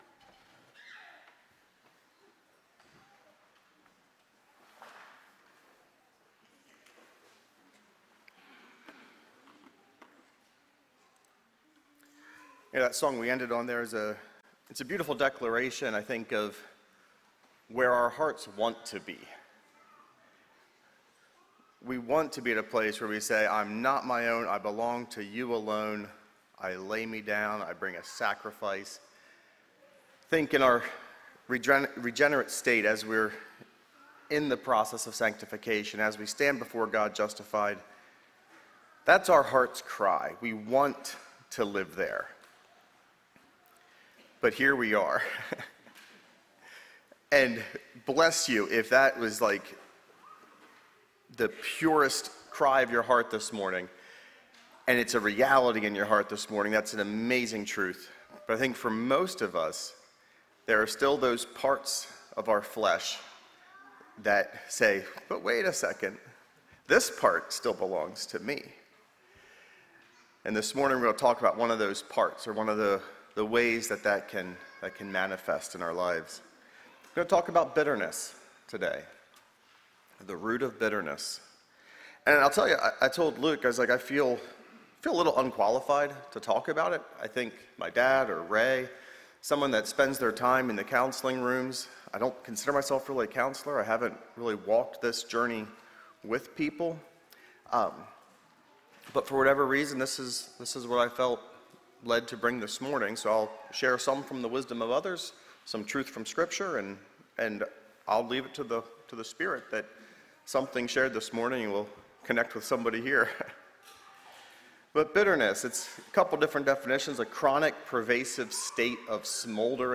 Sermon Archive | - New Covenant Mennonite Fellowship